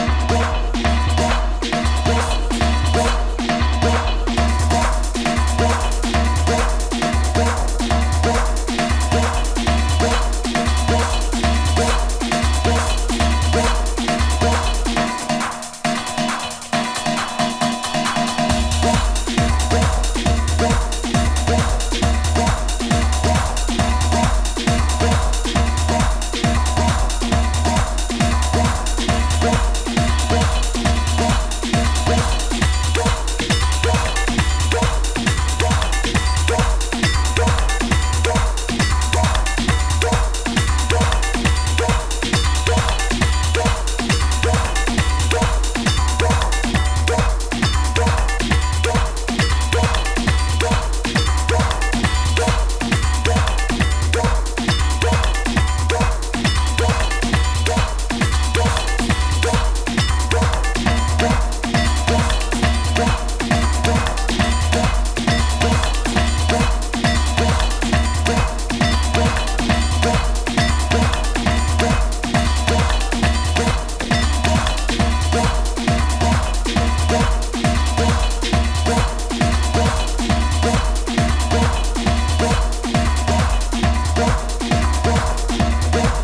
デトロイト感アリのテクノトラック